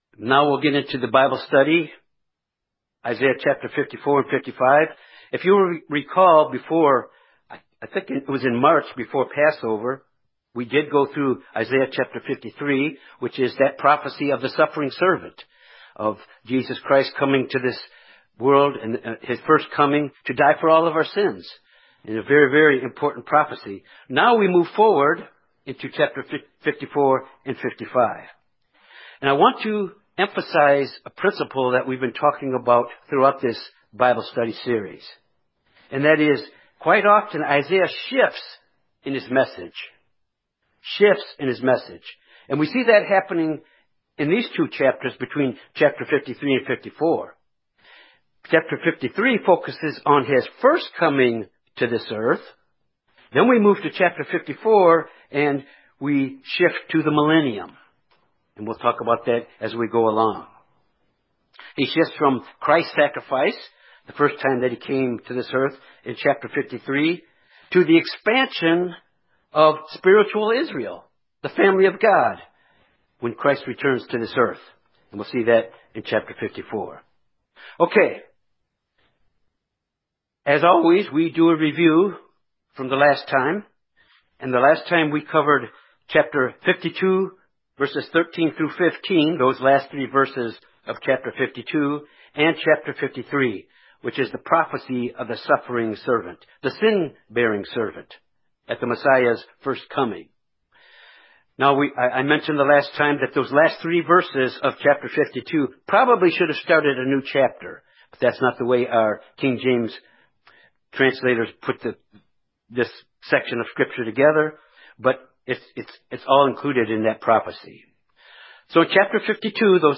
Given in Little Rock, AR Jonesboro, AR Memphis, TN
Bible study Isaiah Studying the bible?